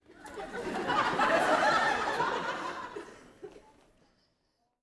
WoW-Sitcomify - Sitcomify is a World of Warcraft AddOn to automatically play random audience laugh tracks at appropriate moments.
Audience Laughing-04.wav